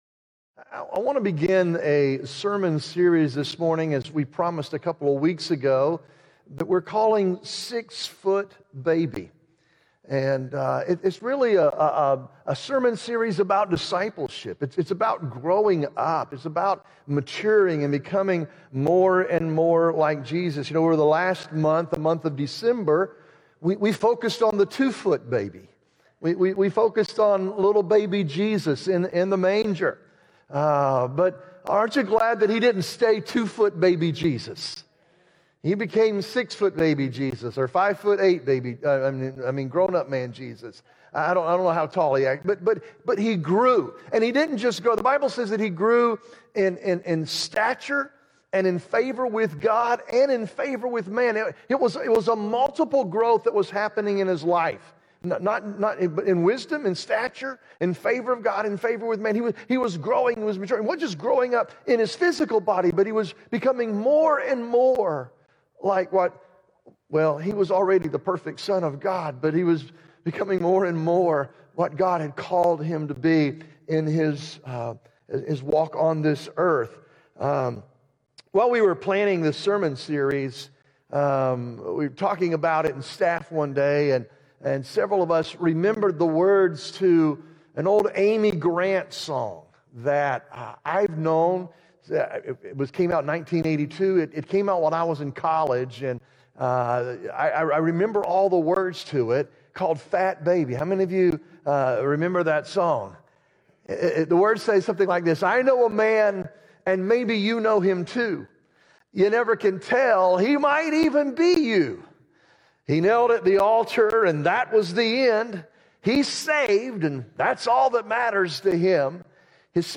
Sermons | SpiritLife Church